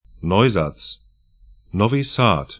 Pronunciation
Neusatz 'nɔyzats Novi Sad 'nɔvi 'sa:d sr Stadt / town 45°15'N, 19°50'E